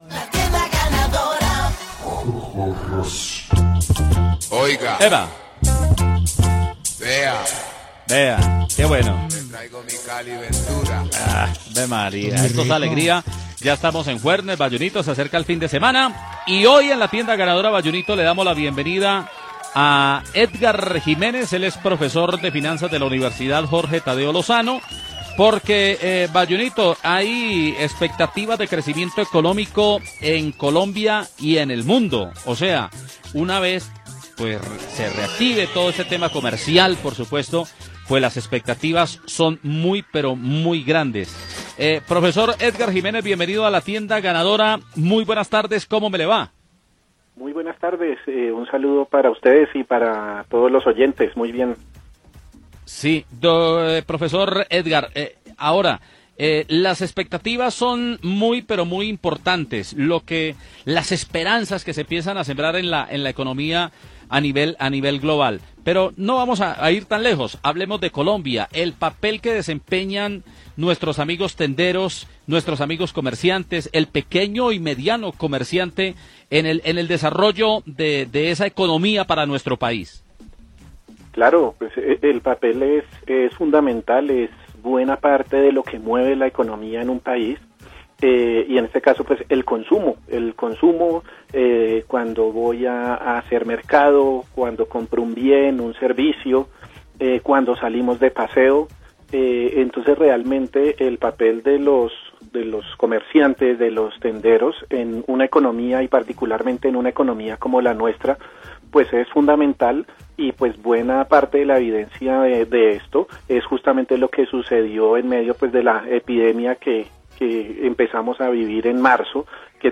Escuche la entrevista completa en La Tienda Ganadora